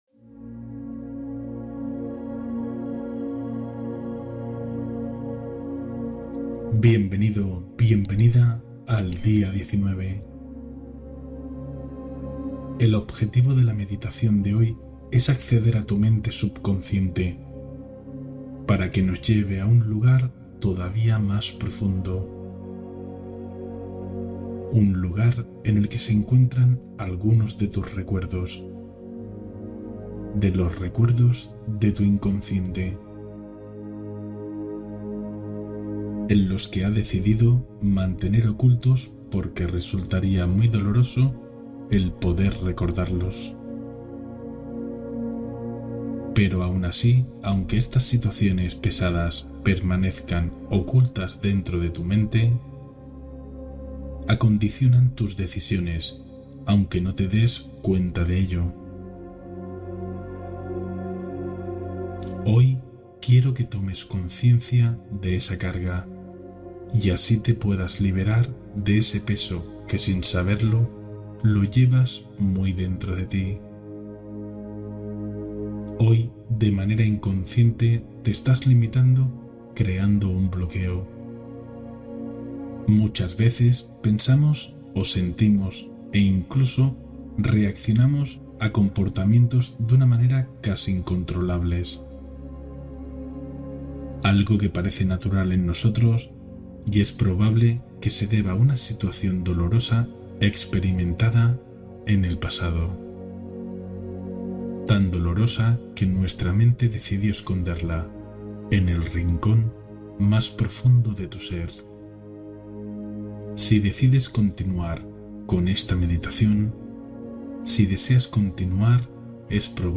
Meditación de Acceso al Subconsciente en Estado de Calma